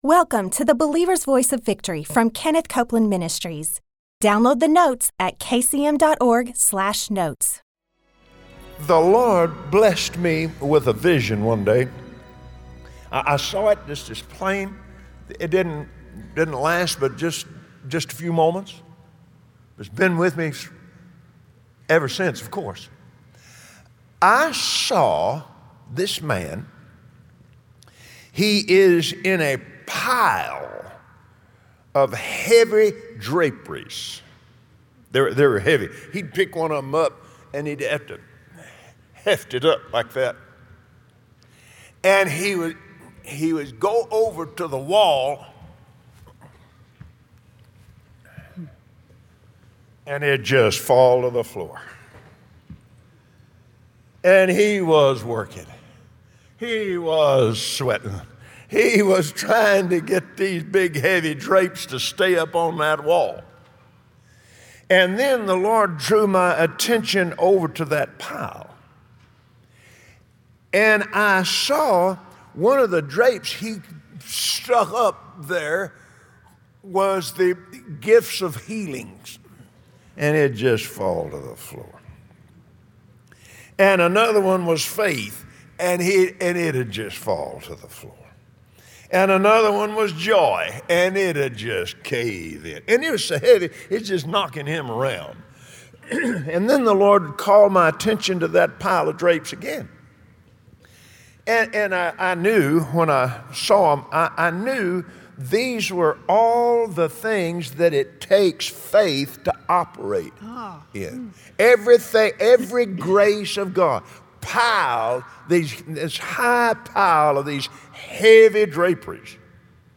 Join Kenneth and Gloria Copeland today, as they pray for your total deliverance and healing. Then watch a powerful testimony of a woman who took God at His Word and was healed from cancer.